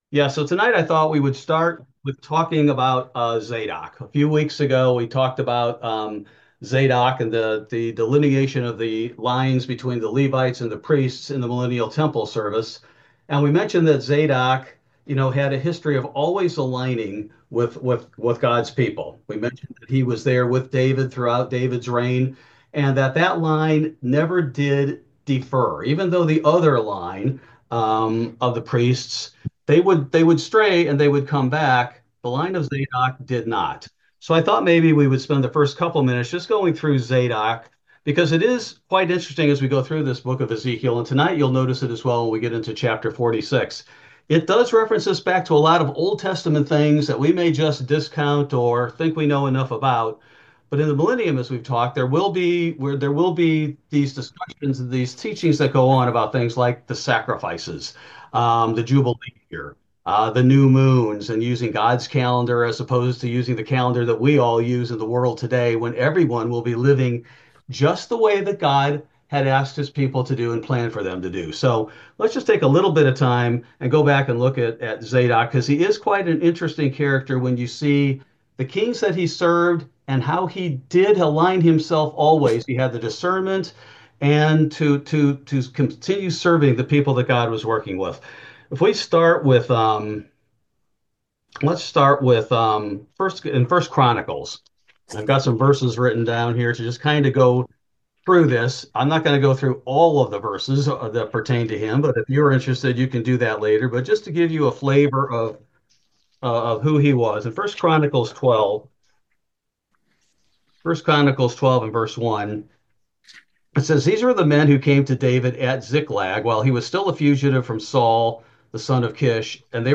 Ezekiel Bible Study: April 16, 2025